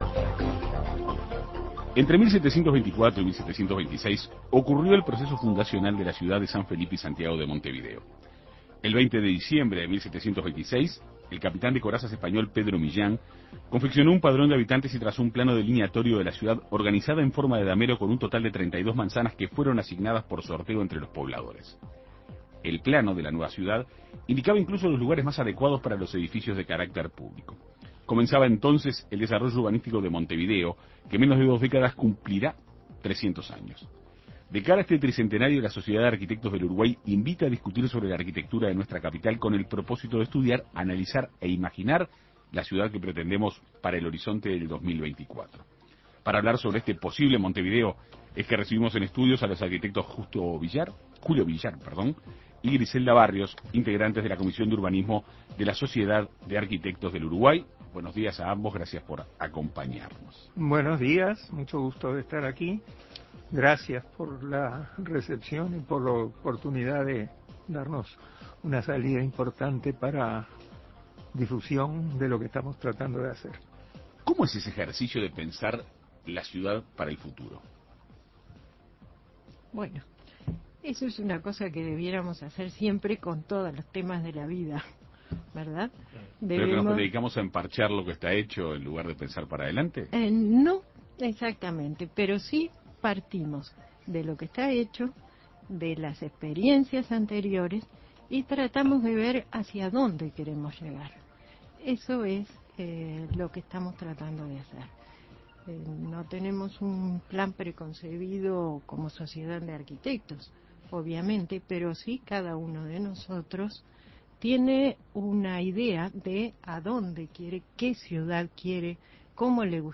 De cara a este tricentenario, la Sociedad de Arquitectos del Uruguay invita a discutir sobre la arquitectura de la capital con el propósito de estudiar, analizar e imaginar la ciudad que se pretende tener en 2024. En Perspectiva Segunda Mañana dialogó con los arquitectos